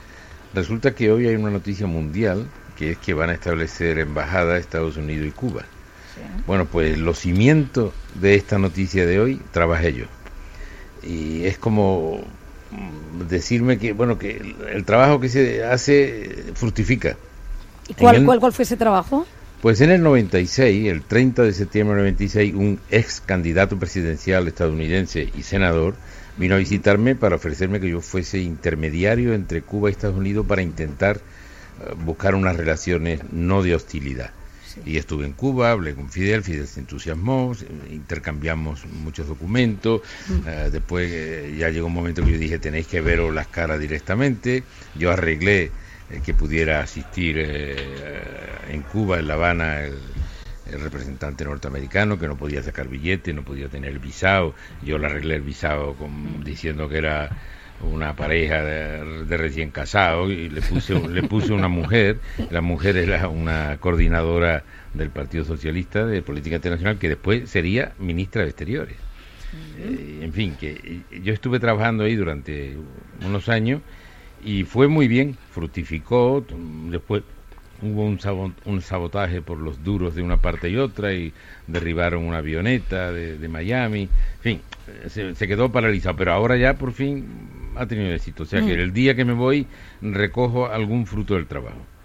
Alfonso Guerra cuenta los entresijos de los contactos que mantuvo con Fidel Castro para desbloquear las relaciones con EE.UU en 1996. Fragmento de la entrevista en Hoy por Hoy, Cadena SER. 18/12/2014